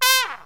SYNTH GENERAL-4 0006.wav